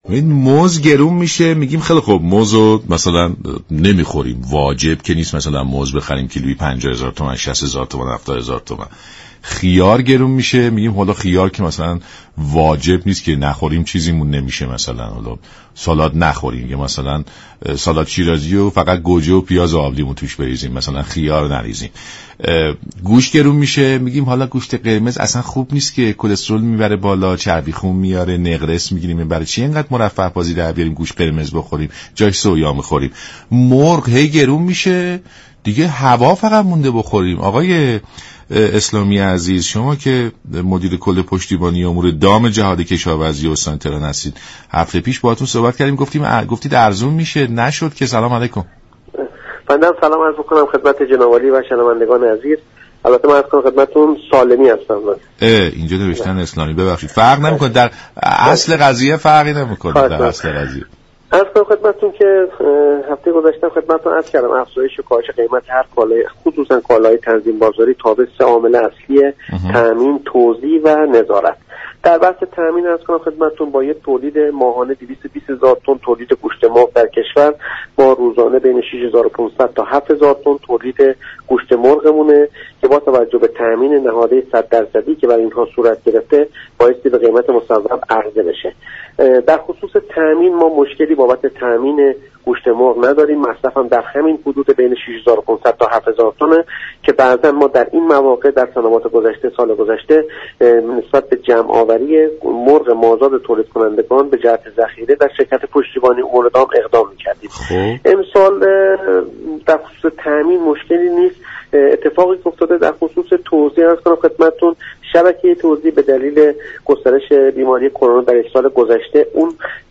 به گزارش شبكه رادیویی ایران، رضا سالمی مدیركل پشتیبانی امور دام تهران در برنامه «سلام صبح بخیر» رادیو ایران درباره علت گرانی قیمت مرغ گفت: افزایش یا كاهش قیمت هر كالایی به ویژه كالاهای ستاد تنطیم بازار تابع سه عامل تامین، توزیع و نظارت است.